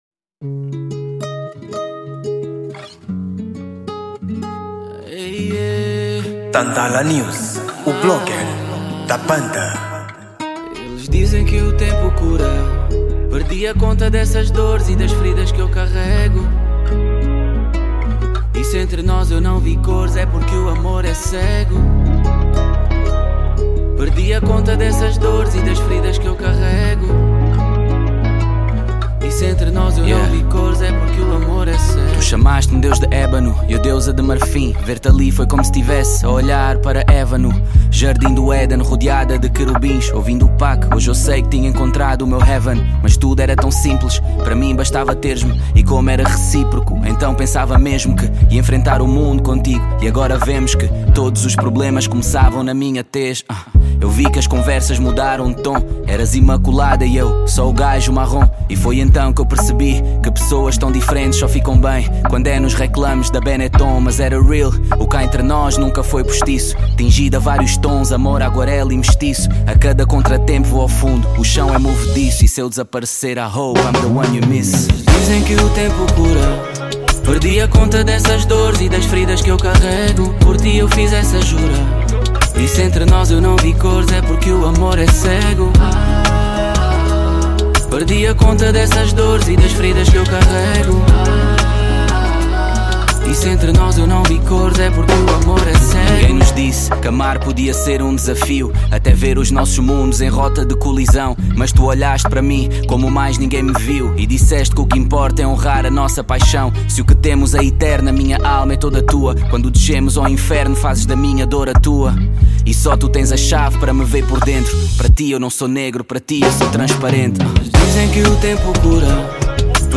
Gênero: Rap